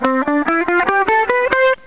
Le guitariste électrique - La gamme majeure
Une gamme majeure se compose de 7 notes séparées par 1T, 1T, 1/2T, 1T, 1T, 1T et 1/2T (T = Ton).